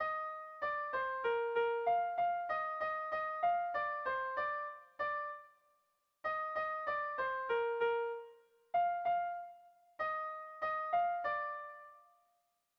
Dantzakoa
Lauko txikia (hg) / Bi puntuko txikia (ip)
AB